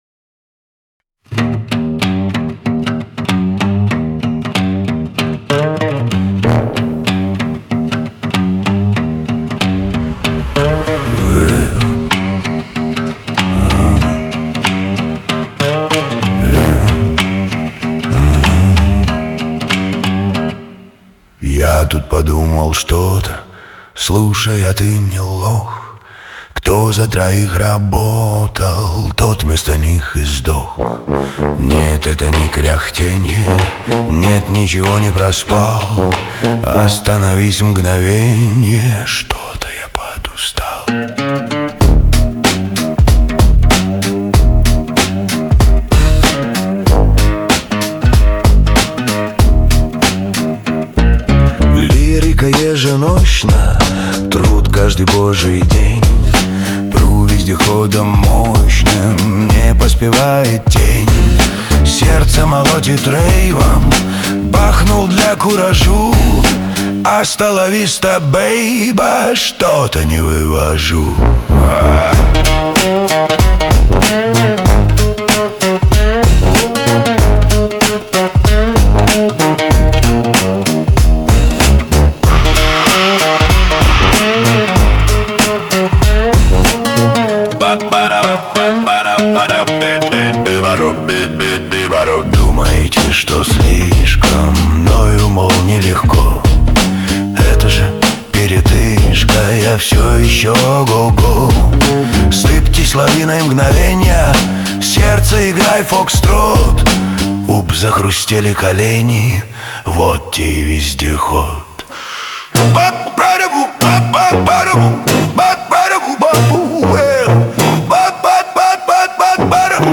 • Исполняет: Suno-генерация
• Аранжировка: Suno
• Жанр: Блюз